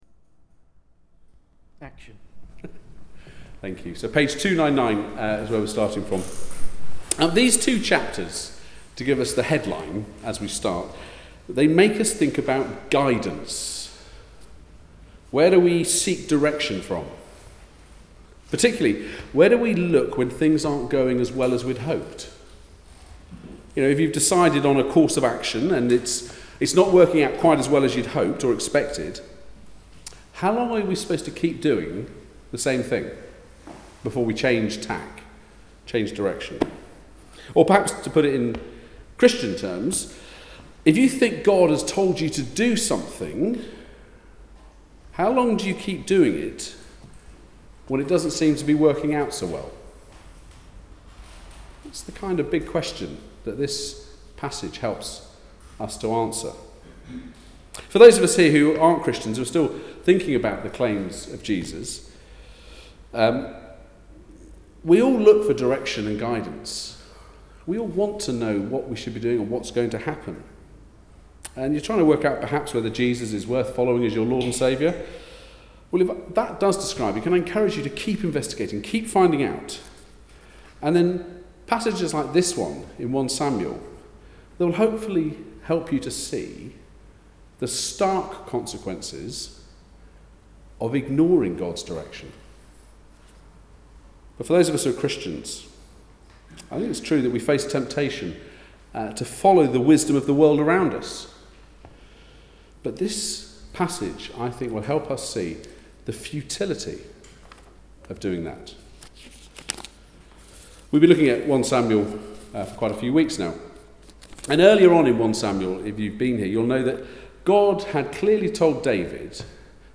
1 Samuel 28-29 Service Type: Weekly Service at 4pm Bible Text
23Jul17-Sermon.mp3